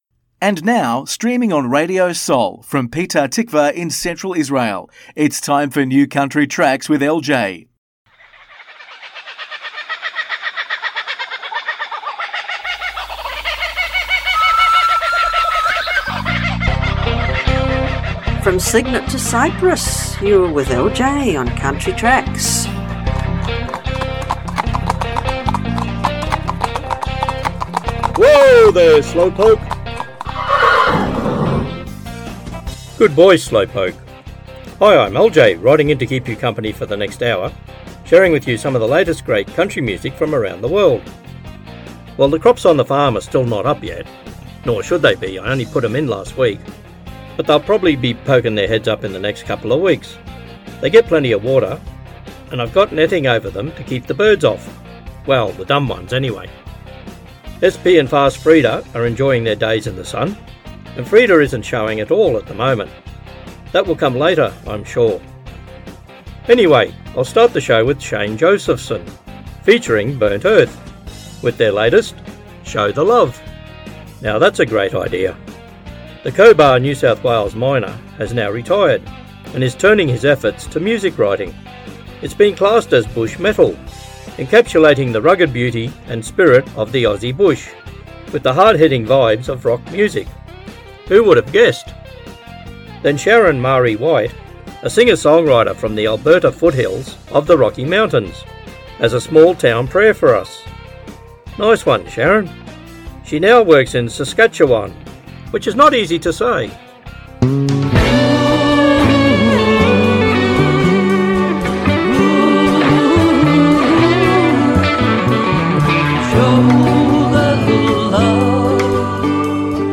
מוזיקת קאנטרי ואינדי עולמית - התכנית המלאה 22.11.24